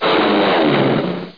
00697_Sound_ski.mp3